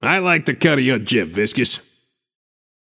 Shopkeeper voice line - I like the cut of your jib, Viscous.